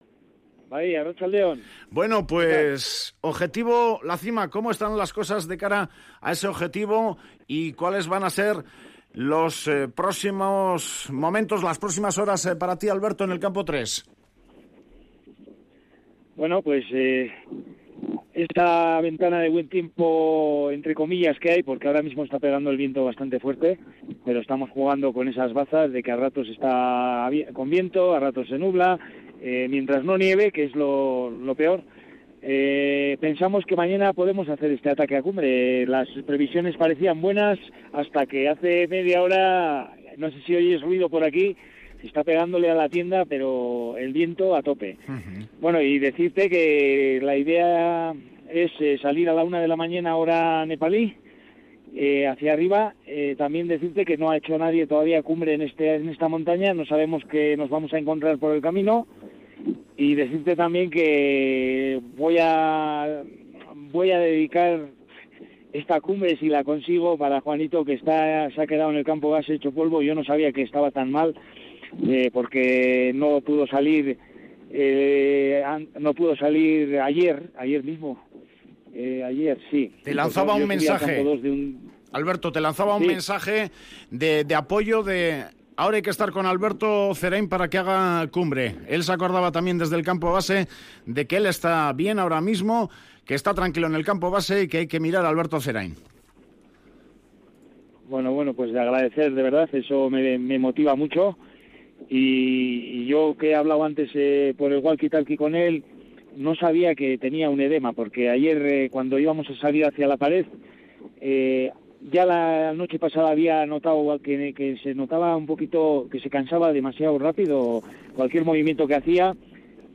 Desde los 7.300 metros de altitud Alberto Zerain descansa en la jornada previa a atacar la cima del Dhaulagiri que podría lograr mañana.